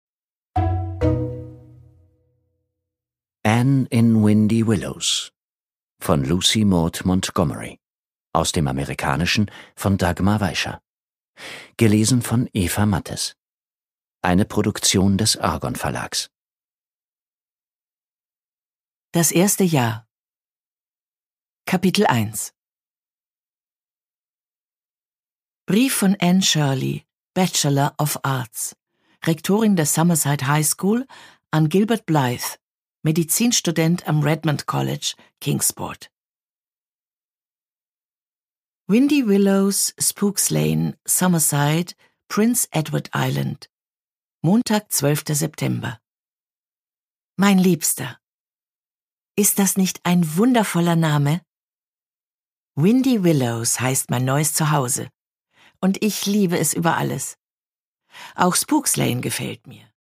Produkttyp: Hörbuch-Download
Gelesen von: Eva Mattes
Wie schon bei ihren beliebten Lesungen der Jane-Austen-Romane entführt sie mit warmem Timbre in eine unvergessene Welt.